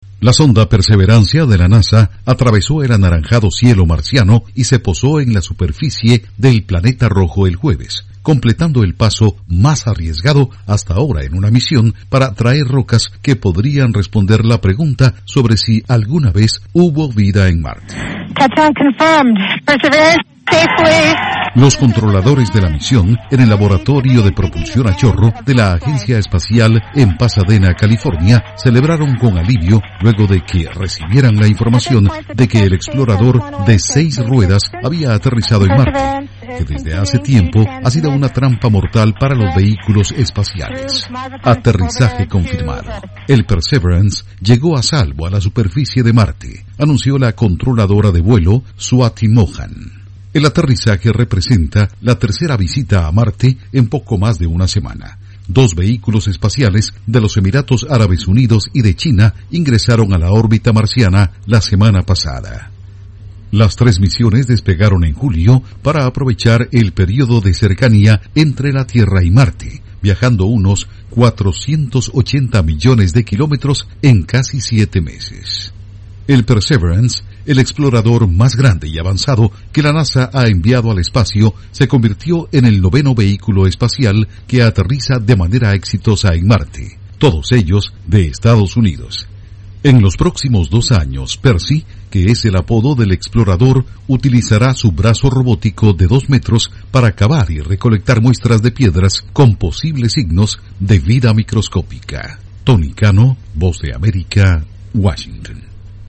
Informa desde la Voz de América en Washington